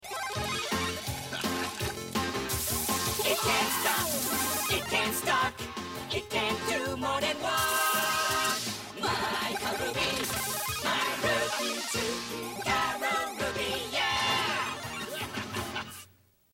Song